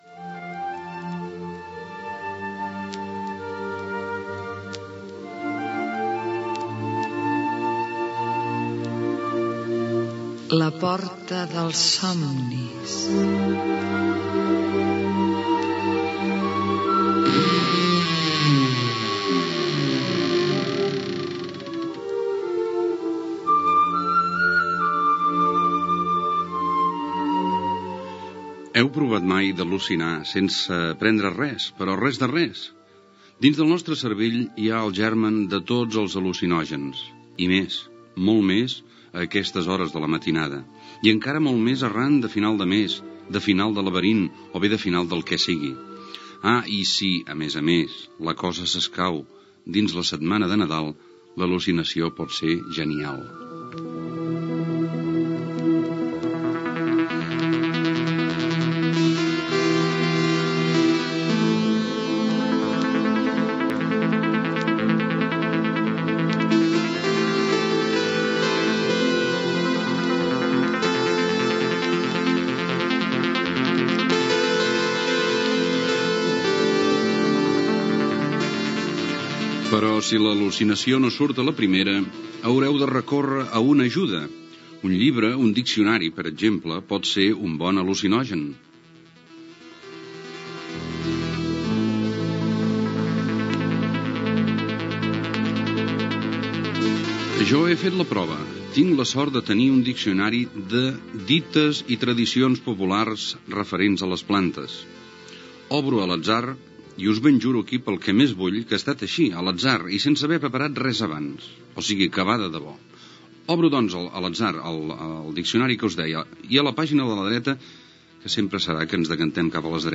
Careta del programa, presentació de l'espai "Al·lucinar per Nadal", una paraula del diccionari de les plantes i la imatge de Déu.
Cultura